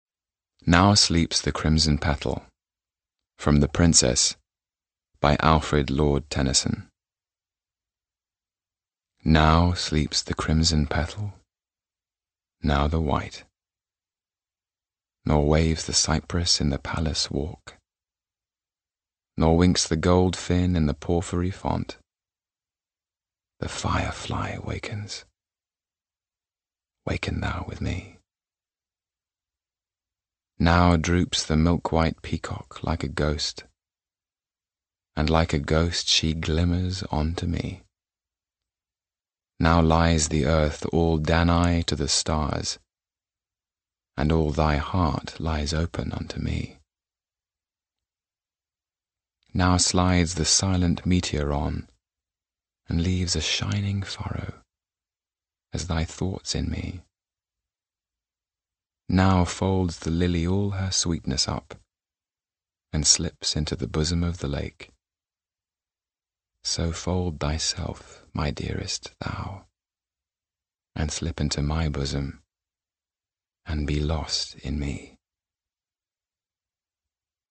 抖森诗歌朗读 第40期:《公主》节选